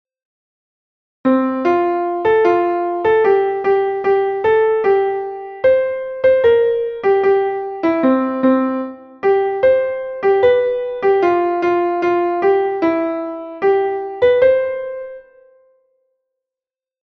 Melodía cun bemol na armadura; despois unha dobre barra e a armadura cambia, non ten alteracións